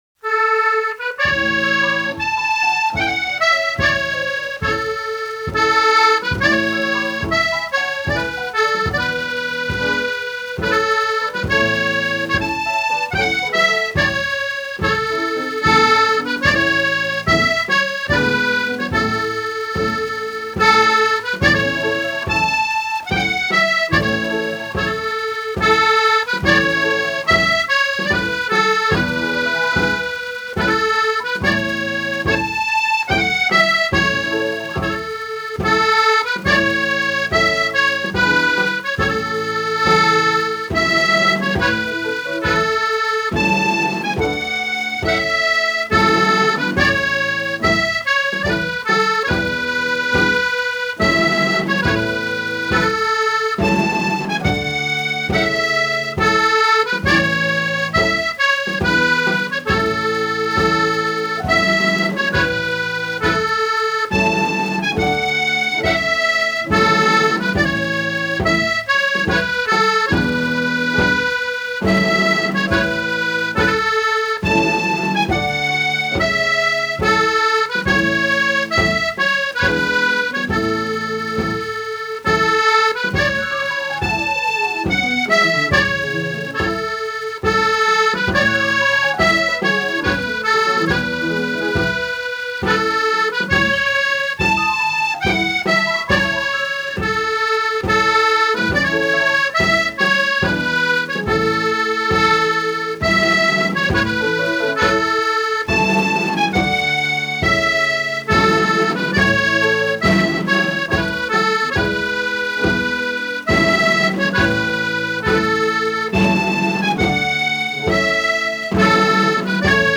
This is a famous pipe retreat.
03 When The Battle’s O’er played by Sir Jimmy Shand RIP